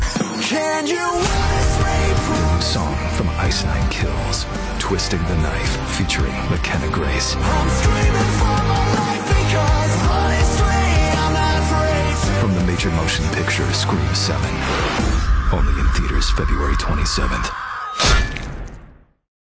TV Spots Download This Spot